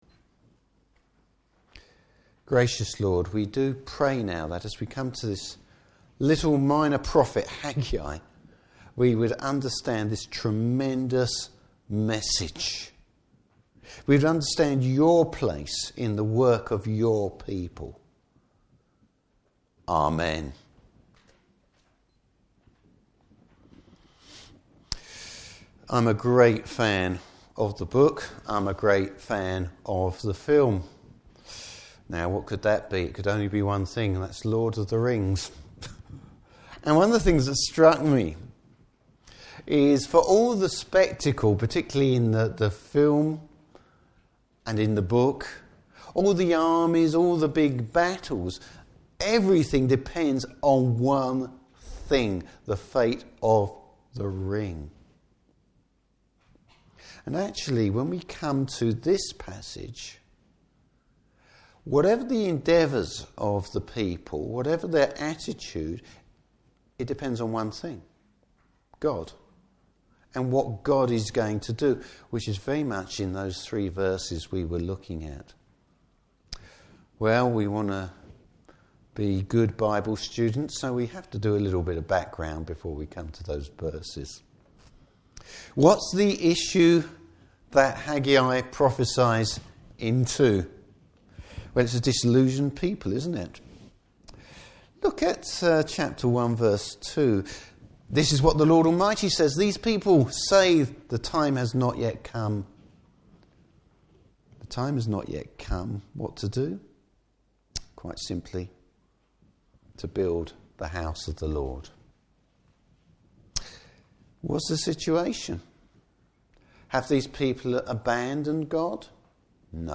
Service Type: Morning Service The temple builders maybe despondent, but the Lord’s got some big plans for it that they could never imagine!